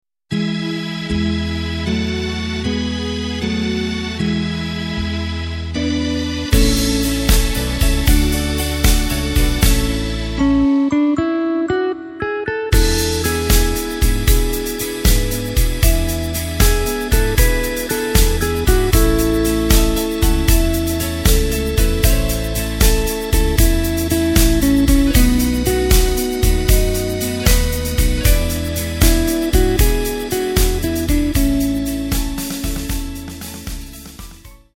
Takt:          3/4
Tempo:         232.00
Tonart:            A
Schlager aus dem Jahr 1969!